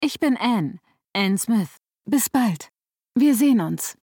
Sprache Ann MP3